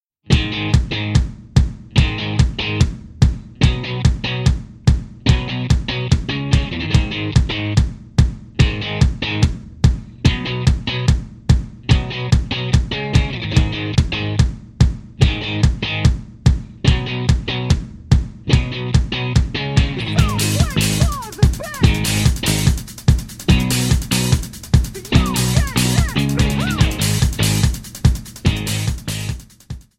D#m
MPEG 1 Layer 3 (Stereo)
Backing track Karaoke
Rock, 2000s